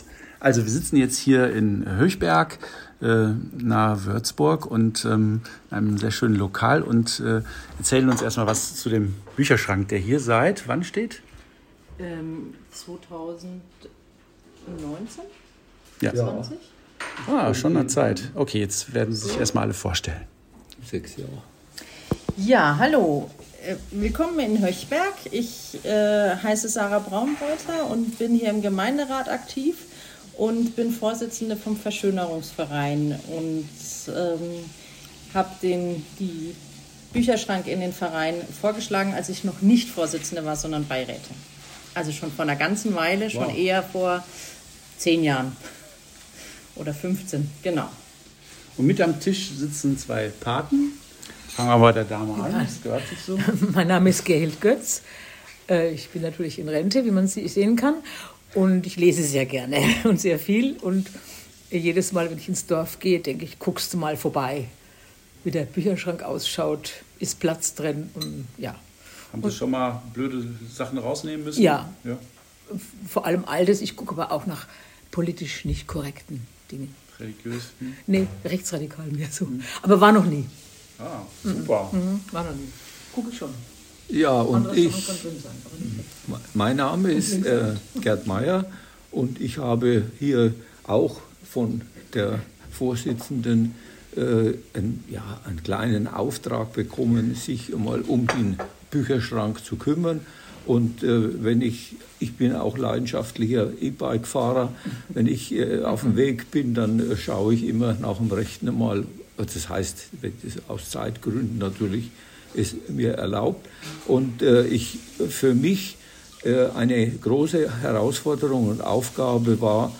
Unser Gespräch im Café hört ihr hier: